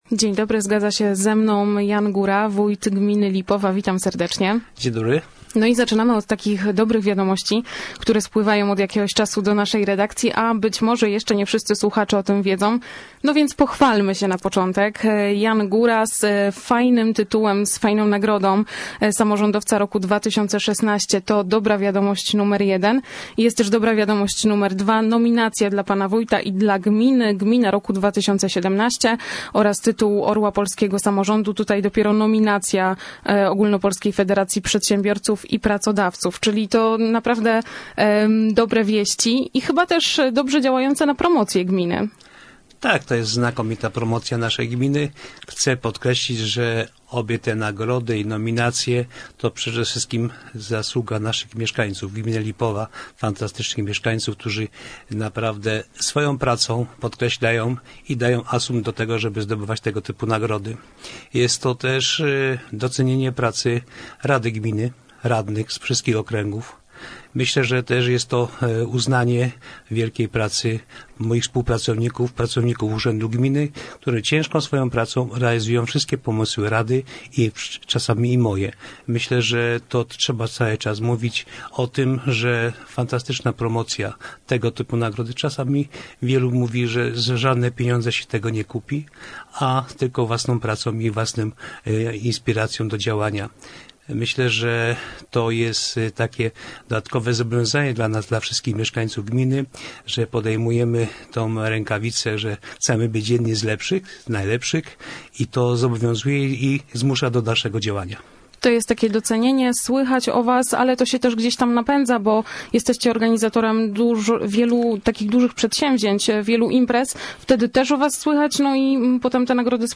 W programie „Gość Dnia” Wójt ogłosił gwiazdy, które zagrają u nas w wakacje.
gosc-radia-bielsko-2017-03-27.mp3